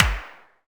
clapOnbeat2.ogg